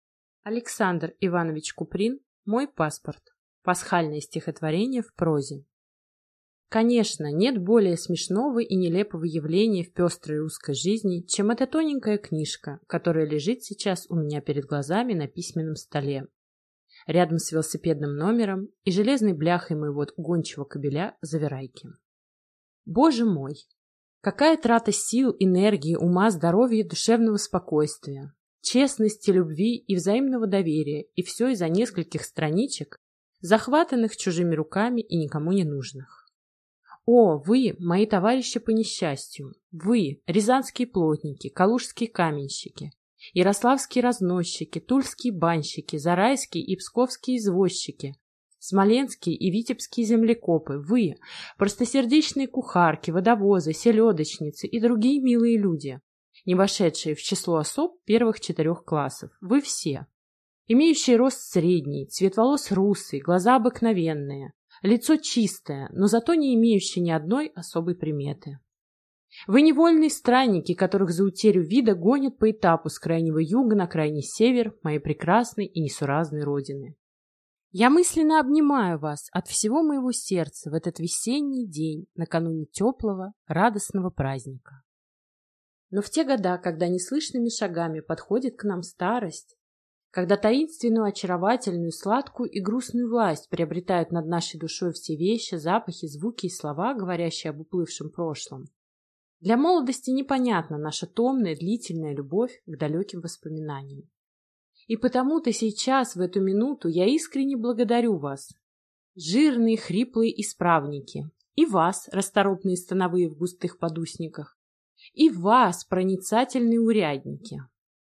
Аудиокнига Мой паспорт | Библиотека аудиокниг